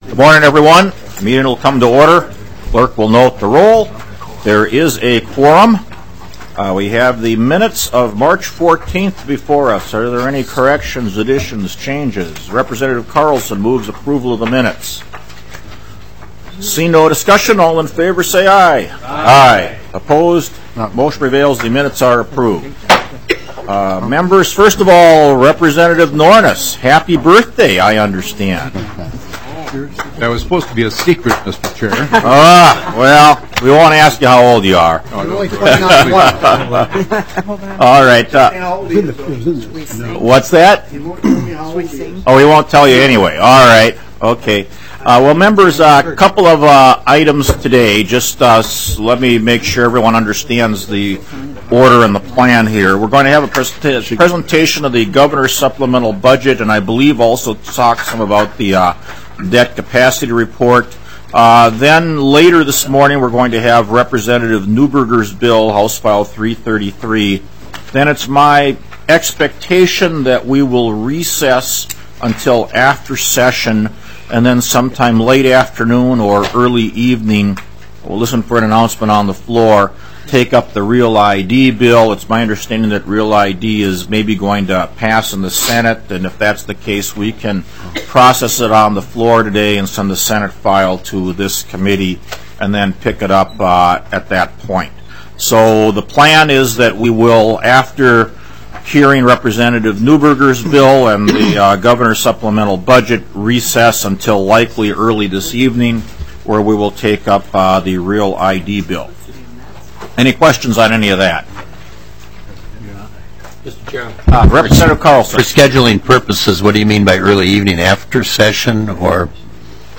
Gavel and introductory remarks.